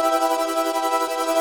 Index of /musicradar/shimmer-and-sparkle-samples/170bpm
SaS_MovingPad01_170-E.wav